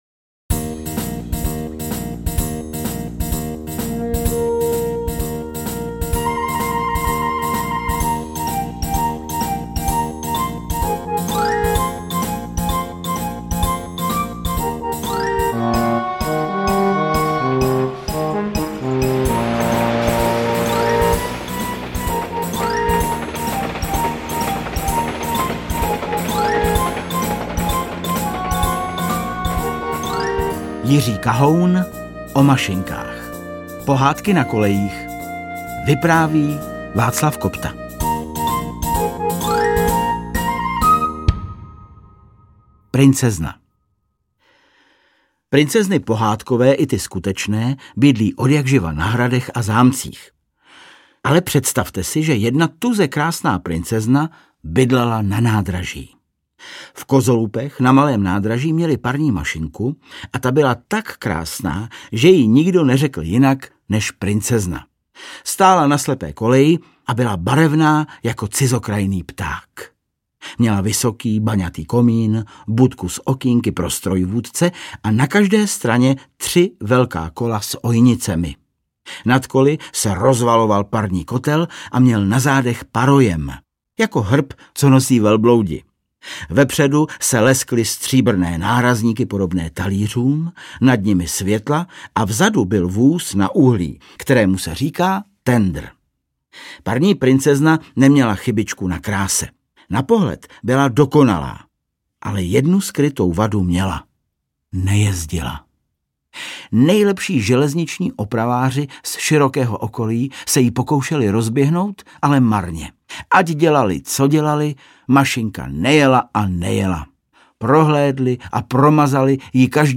• AudioKniha ke stažení Kahoun: O mašinkách - Pohádky na kolejích
Interpreti:  Václav Kopta, Václav Kopta, Václav Kopta